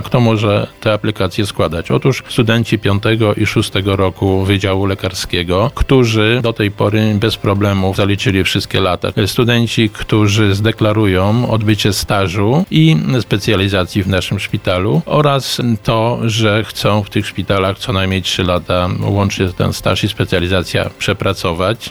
Aplikacje do programu stypendialnego złożyć można do 31 marca bieżącego roku, a kto będzie mógł z niego skorzystać mówi- Krzysztof Kozera- Wicestarosta Powiatu radomskiego: